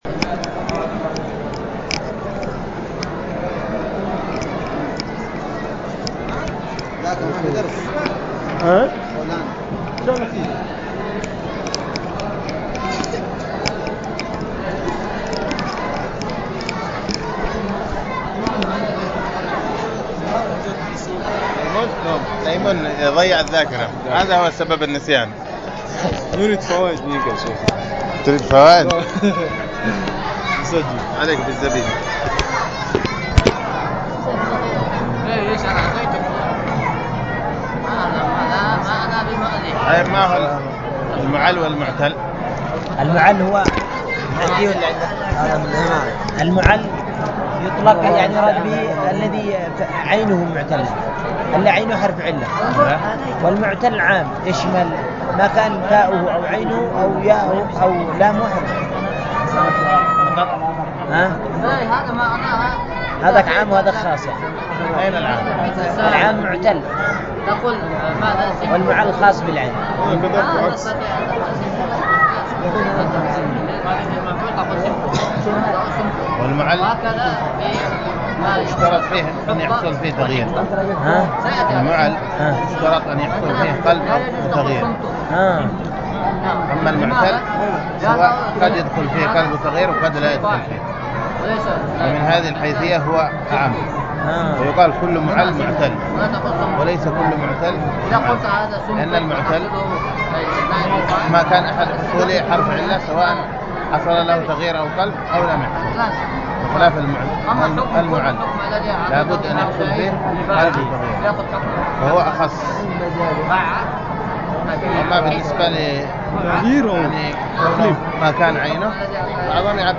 التعليق على شرح ابن عقيل على ألفية ابن مالك- مكتمل
ألقيت بدار الحديث بدماج